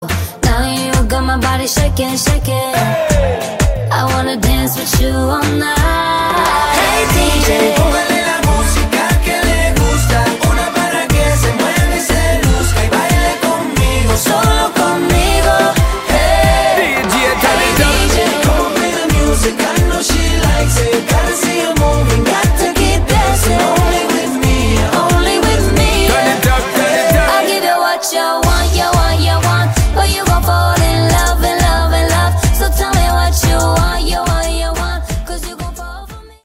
Pop